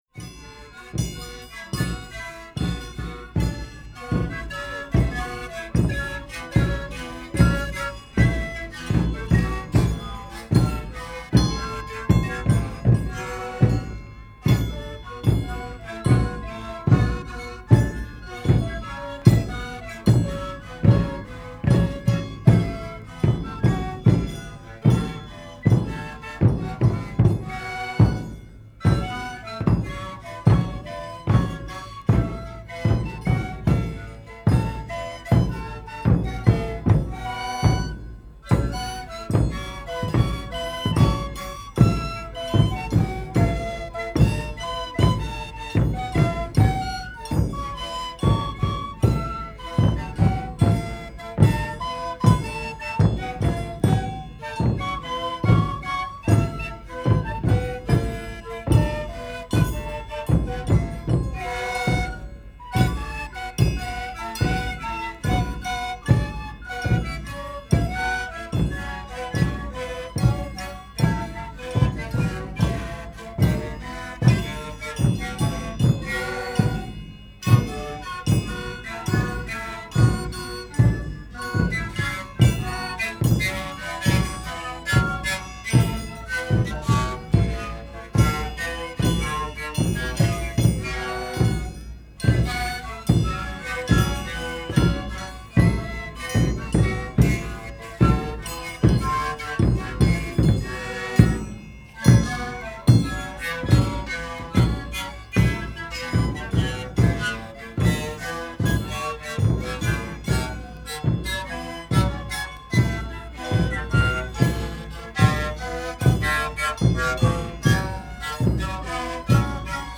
Canto de Apacheta (música quechua)